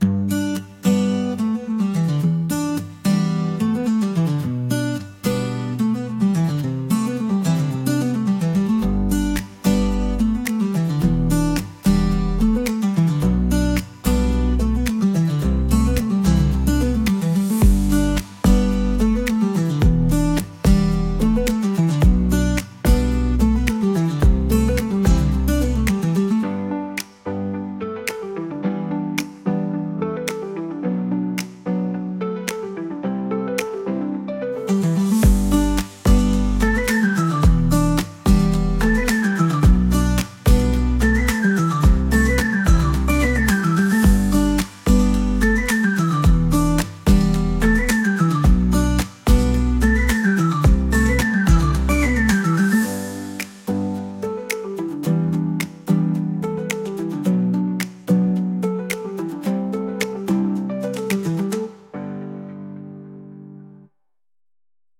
大人な雰囲気